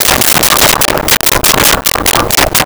Chickens In Barn 04
Chickens in Barn 04.wav